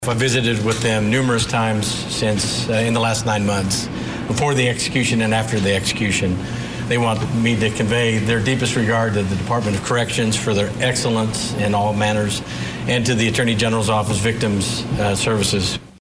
Oklahoma Attorney General Genter Drummond was at Thursday's execution and he said the family